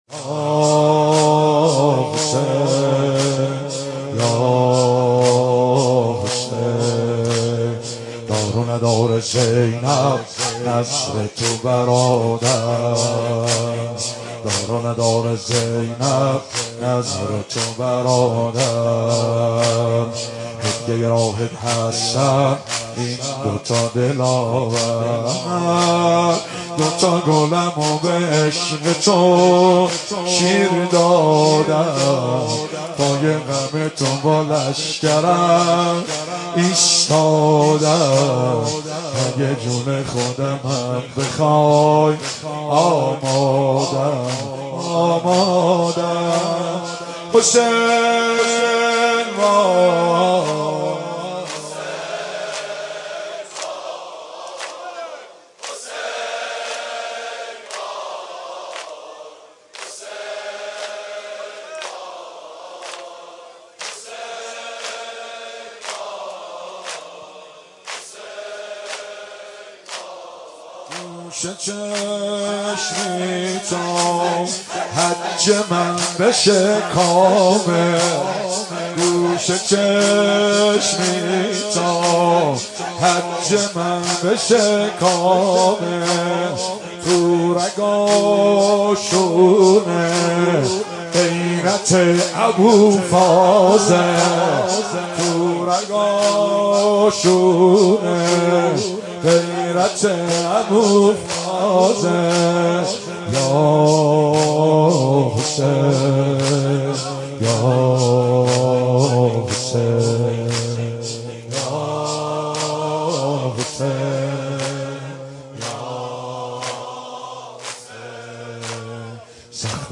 شب چهارم محرم 96
مداحی اربعین